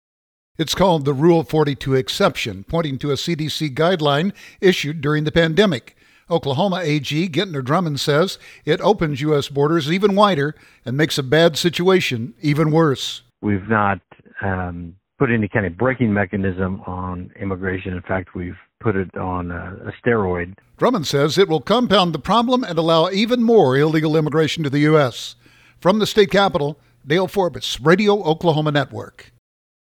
“We have not put any kind of breaking mechanism on immigration,” Drummond said.